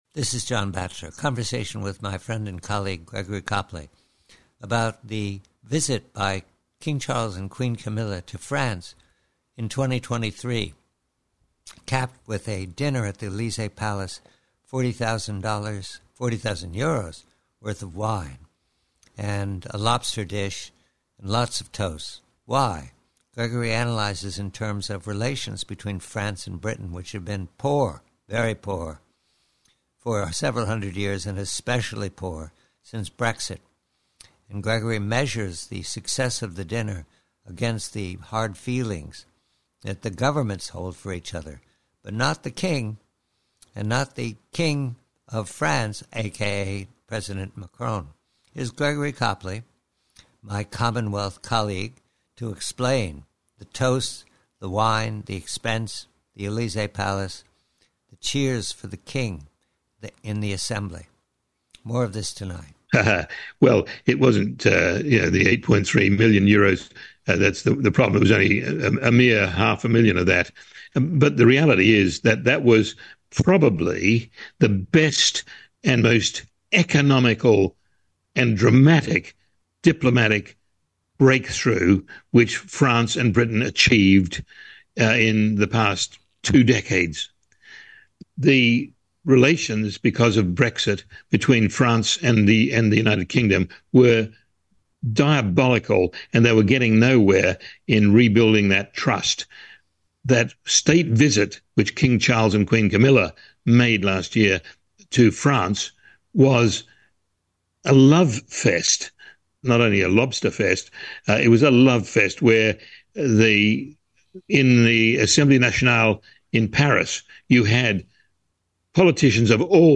PREVIEW: ELYSEE: KING CHARLES: Conversation